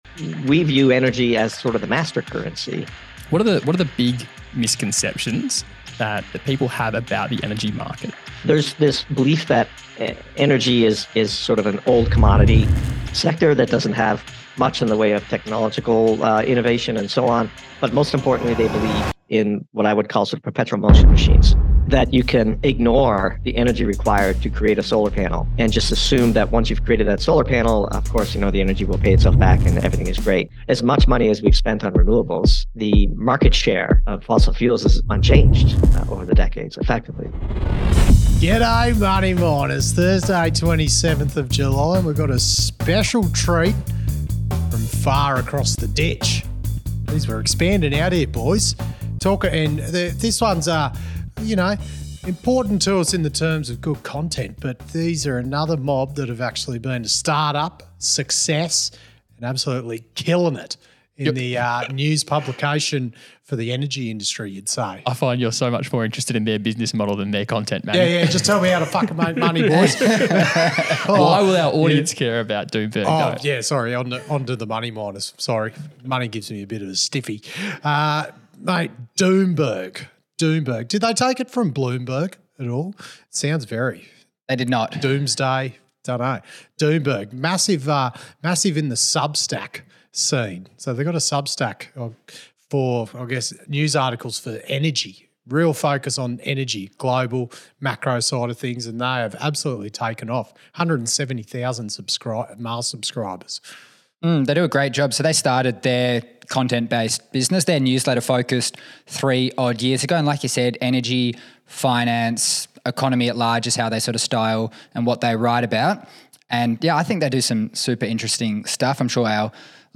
We have the delight of being able to share today with the Money Miners a discussion with Doomberg! For those unaware, Doomberg represent a small team of skilled analysts who through their substack newsletter write provocative and thought-provoking content in the macro, energy, finance, and economy-at-large space.